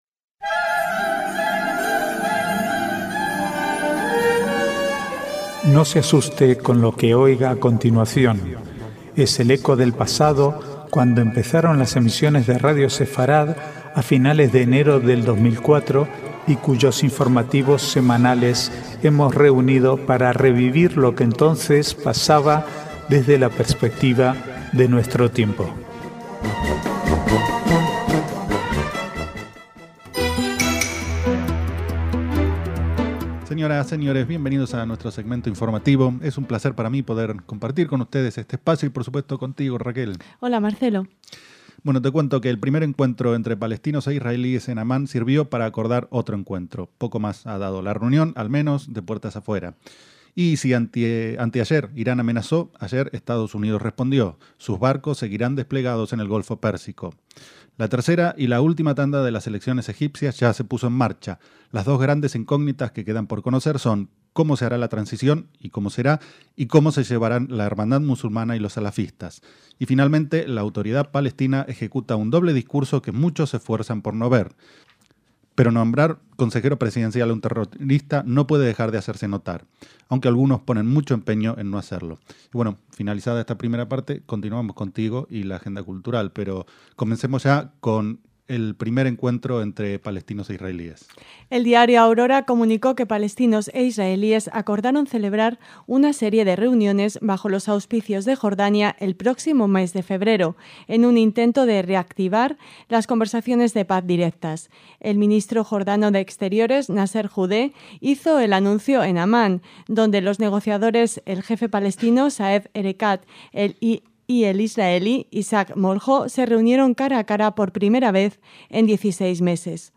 Archivo de noticias del 5 al 11/1/2012